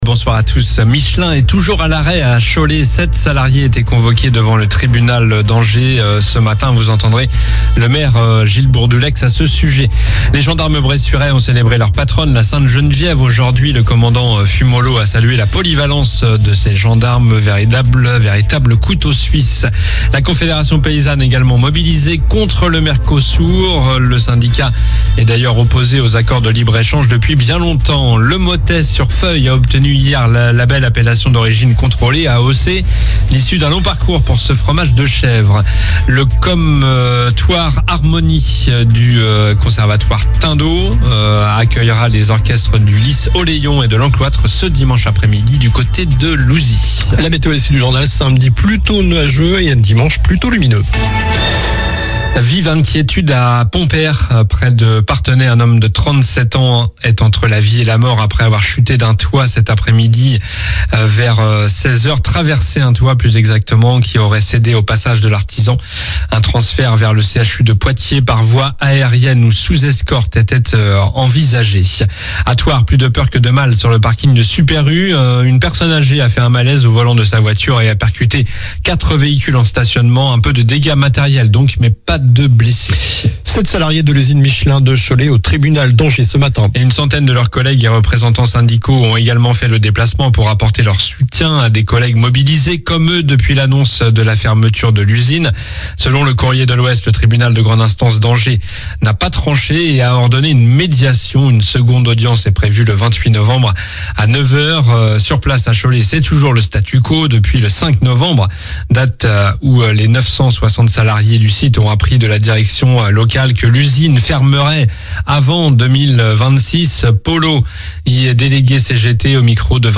Journal du vendredi 22 novembre (soir)